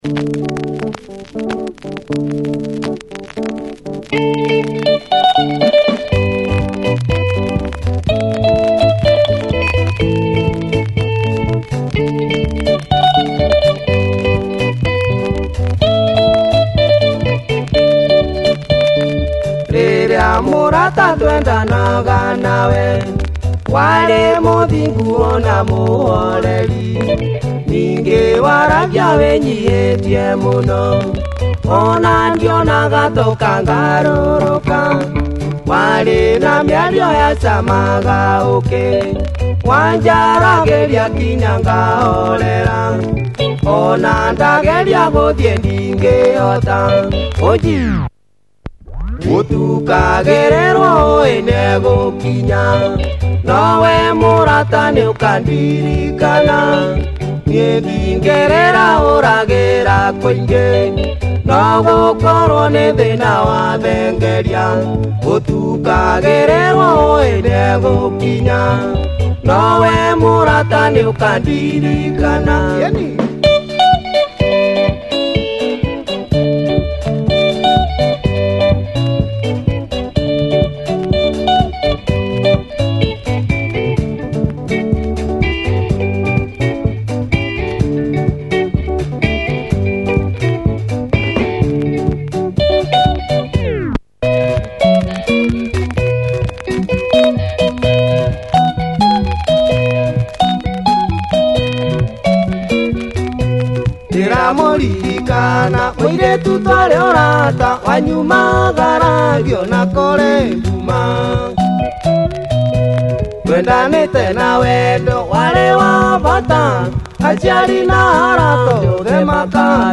a more traditional outing here.